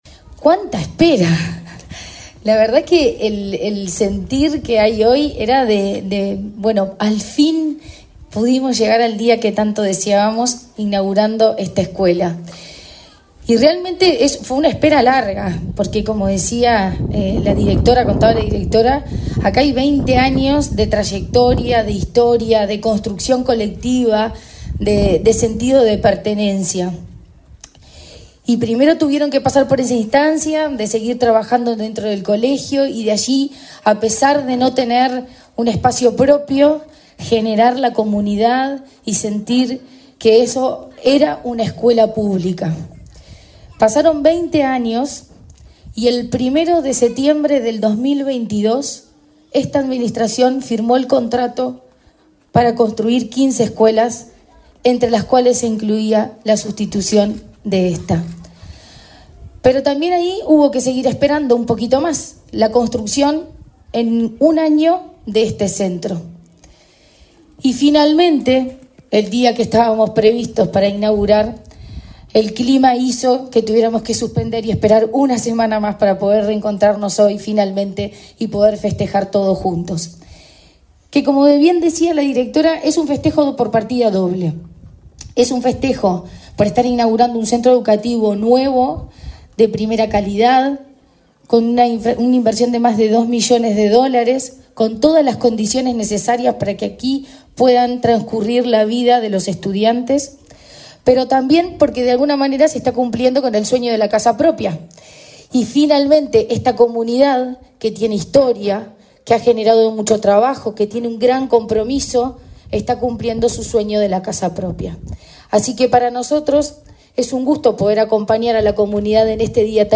Palabras de autoridades de la ANEP
Palabras de autoridades de la ANEP 02/05/2024 Compartir Facebook X Copiar enlace WhatsApp LinkedIn La presidenta de la Administración Nacional de Educación Pública (ANEP), Virginia Cáceres, y la directora general de Educación Primaria, Olga de las Heras, participaron de la inauguración del nuevo edificio de la escuela n.°369, este jueves 2, en Pajas Blancas, en Montevideo.